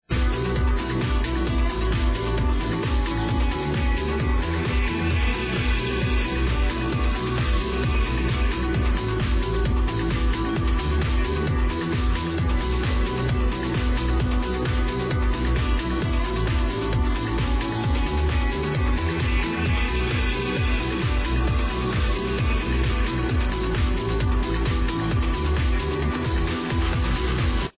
Heard this on the radio.